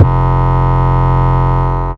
Index of /Antidote Advent/Drums - 808 Kicks
808 Kicks 13 F.wav